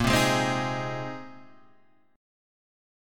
A#7sus2sus4 chord